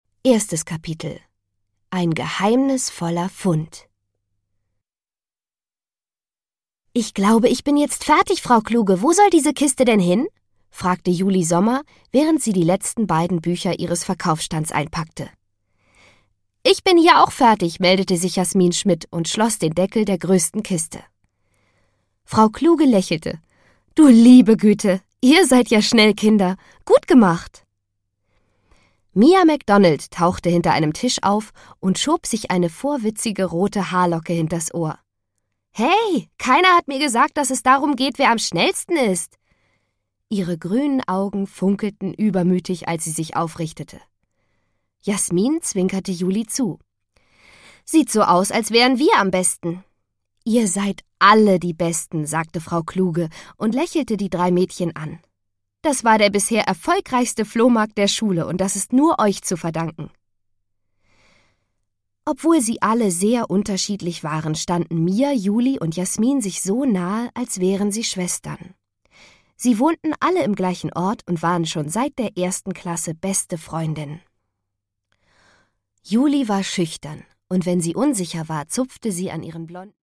Produkttyp: Hörbuch-Download
Fassung: Ungekürzte Lesung